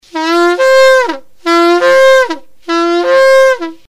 During the blowing of the shofar on Rosh Hashana there are three distinct sounds.
shevarim.mp3